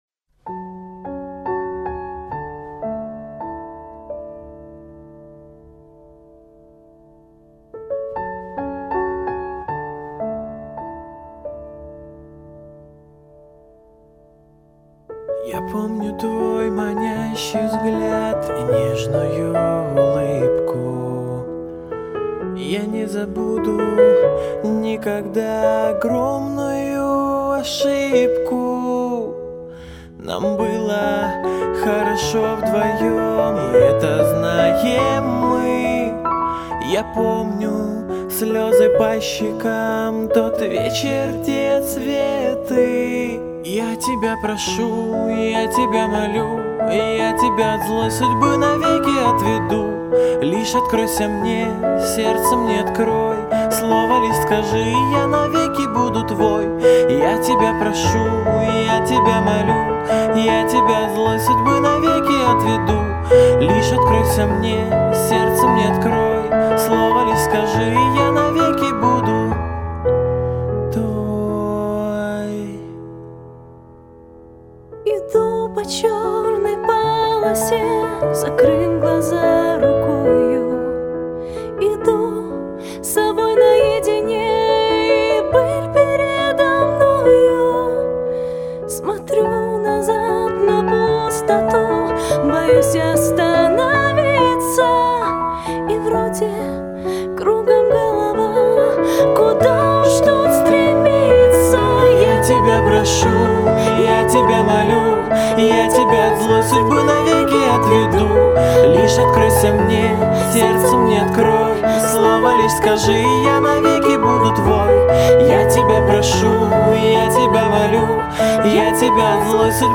Стиль исполнения: поп-музыка